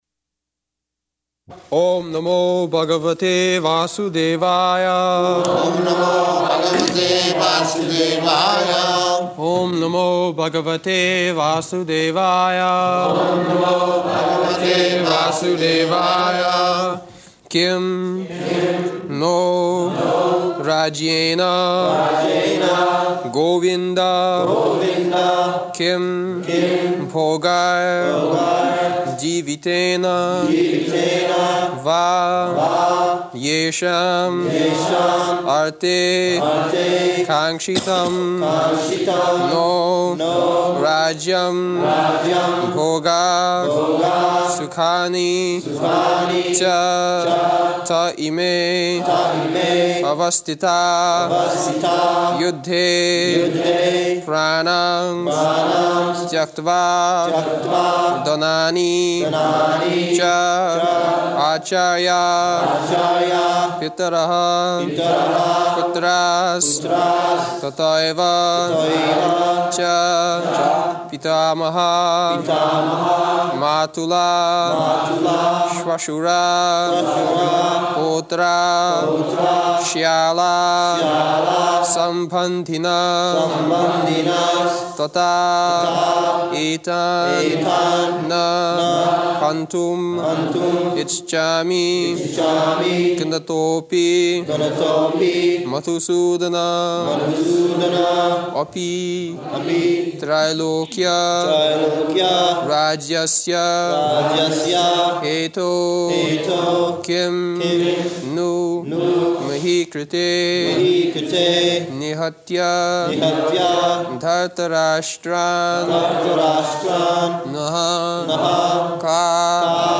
July 25th 1973 Location: London Audio file
[Prabhupāda and devotees repeat] [leads chanting of verse]